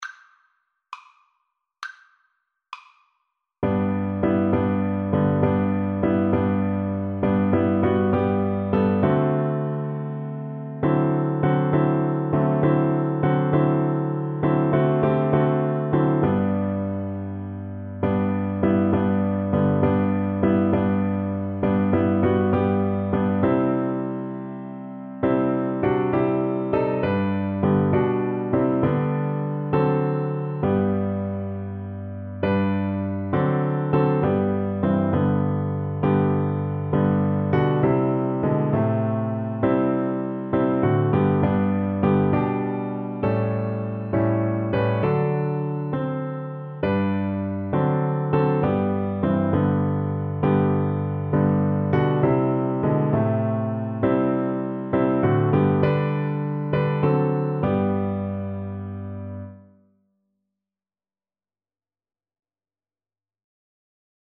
6/8 (View more 6/8 Music)